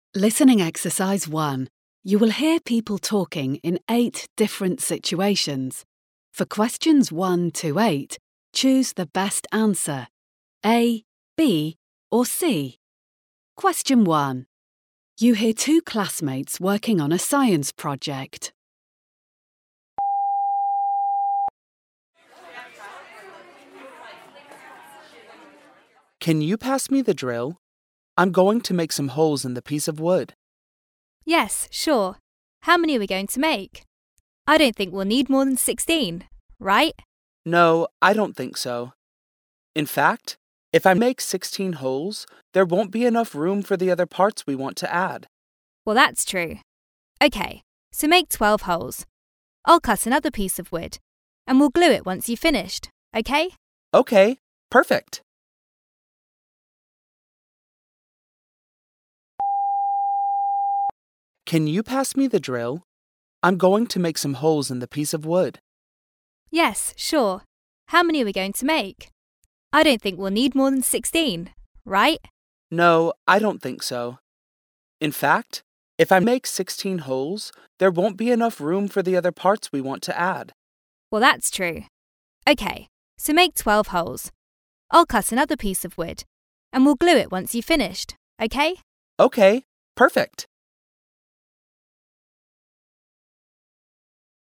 Doblaje de curso de enseñanza del inglés.